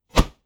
Close Combat Swing Sound 44.wav